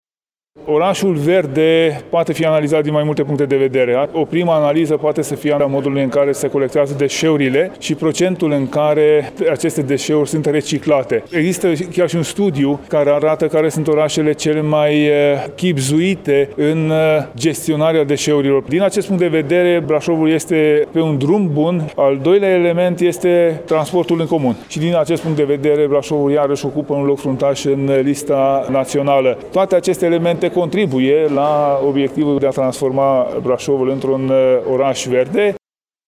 Prezent la Brașov, la Forumul Orașelor verzi, Tanczos Barna a ținut să precizeze că fiecare oraș din țară trebuie să consume responsabil resursele energetice și să gestioneze corespunzător deșeurile: